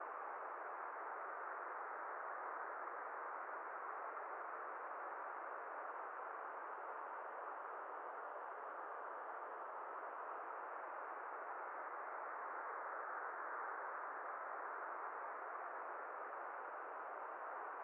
wind2.ogg